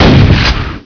shotgun1.wav